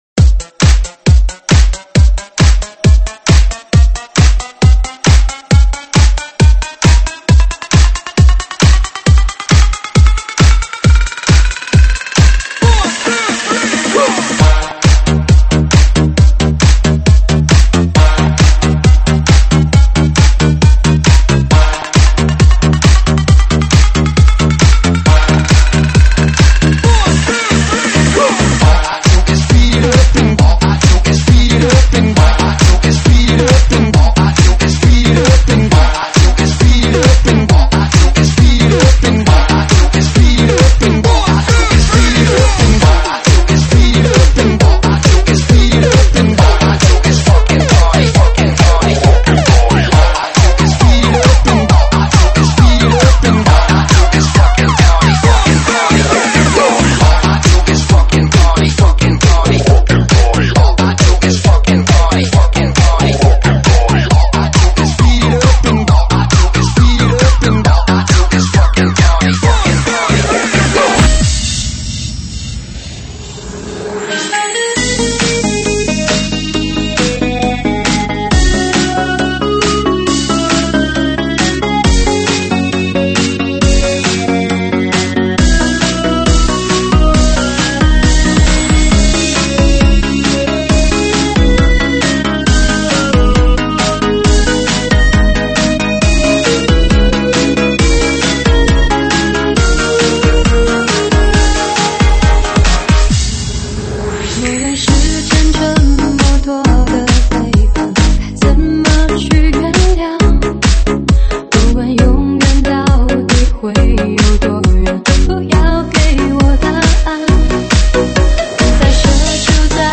中文慢摇
舞曲类别：中文慢摇